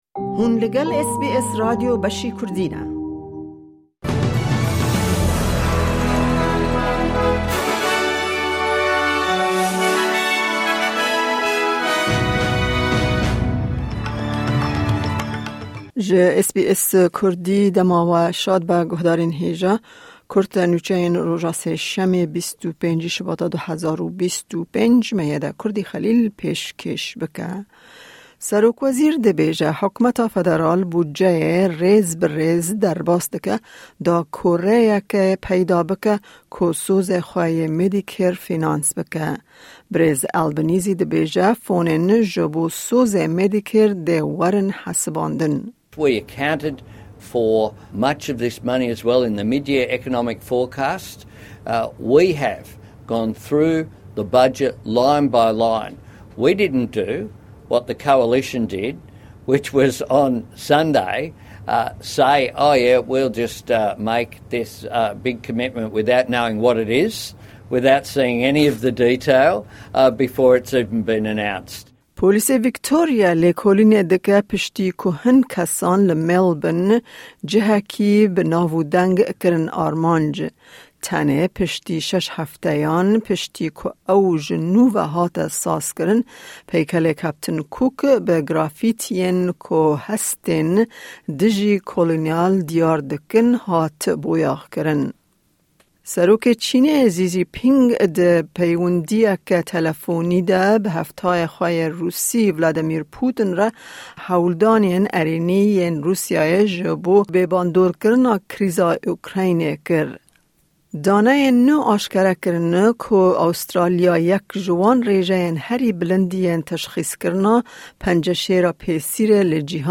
Kurte Nûçeyên roja Sêşemê, 25î Şibata 2025